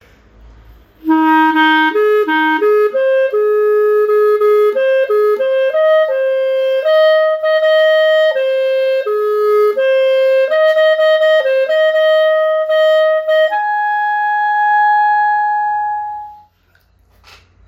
Quer ouvir o toque de presença de ex-combatente?